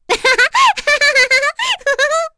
Cecilia-Vox_Happy3.wav